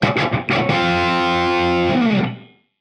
AM_HeroGuitar_85-F02.wav